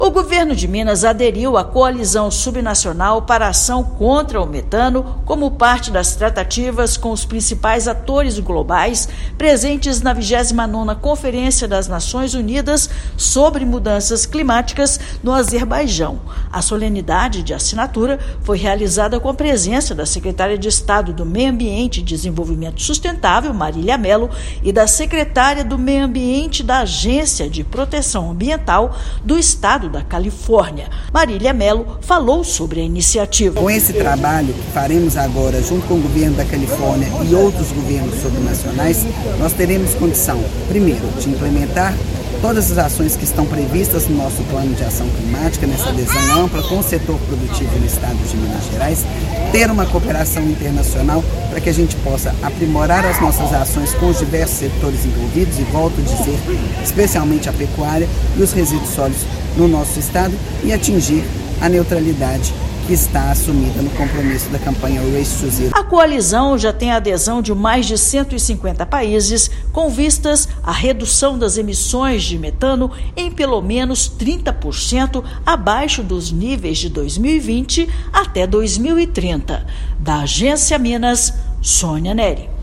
Parceria pode catalisar investimentos para a descarbonização da economia em Minas Gerais. Ouça matéria de rádio.